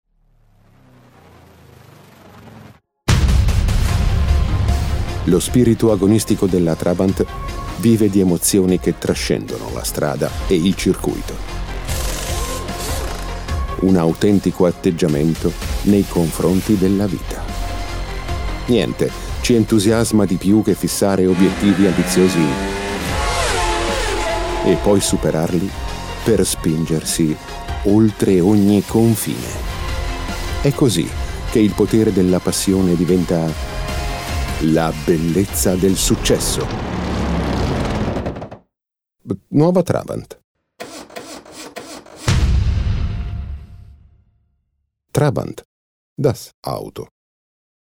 I am a native Italian voice actor talent for documentaries, e-learning, infomercials, audio-guides, media content, advertisements and audio book narrations
Kein Dialekt
Sprechprobe: Werbung (Muttersprache):